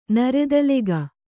♪ naredalega